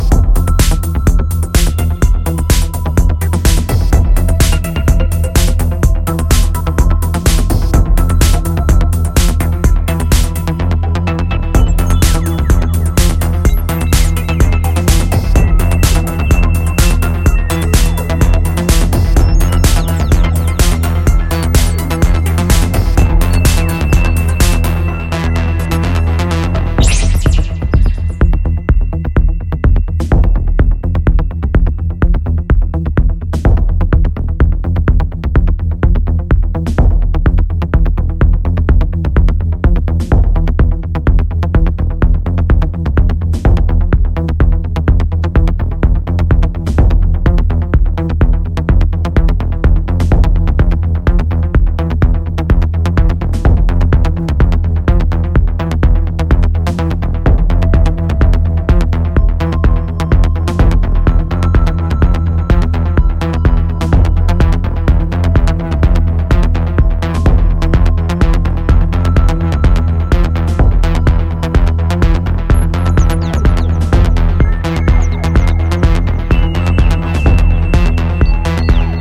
a complete pure analog ep